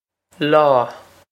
law
This is an approximate phonetic pronunciation of the phrase.